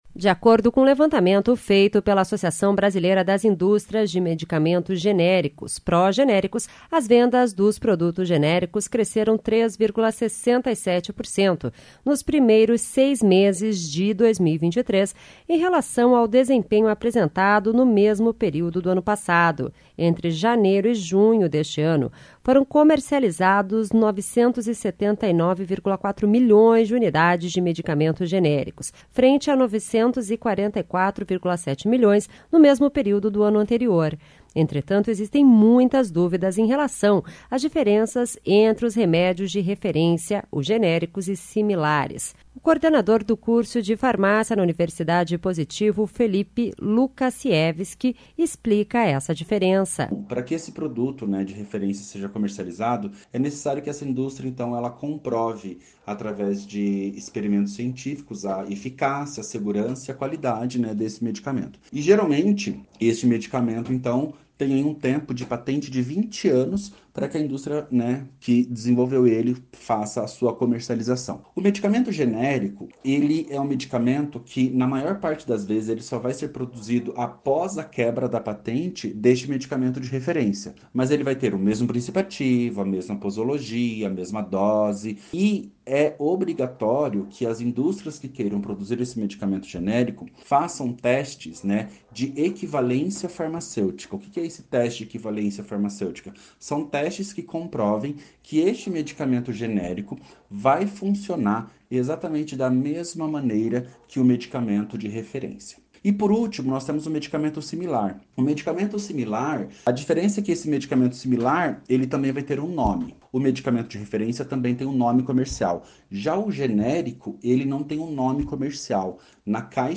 O especialista explica em relação aos preços dos medicamentos.